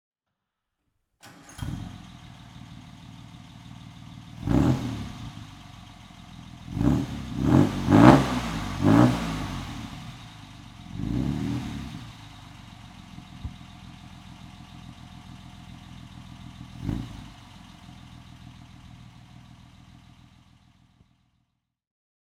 Jaguar SS 100 2.5 Litre (1938) - Starten und Leerlauf